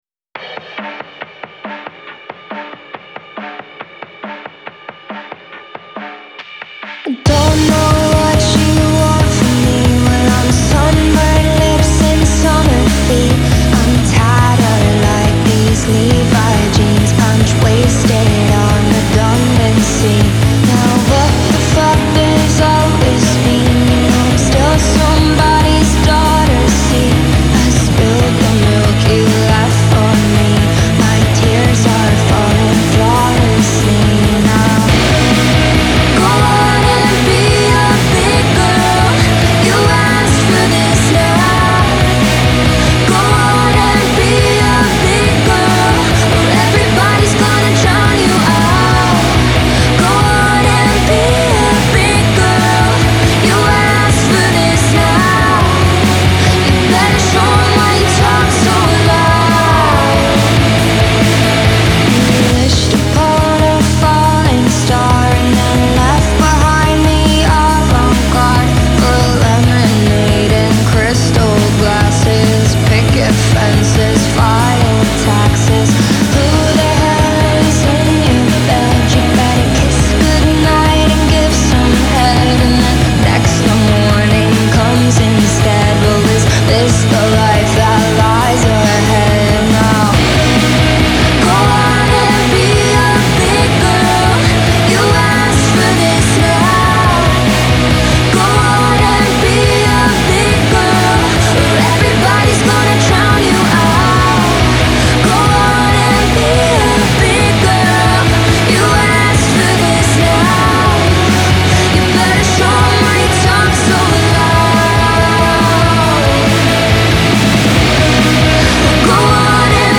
Genre : Pop, Alternative